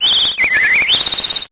Cri de Minisange dans Pokémon Épée et Bouclier.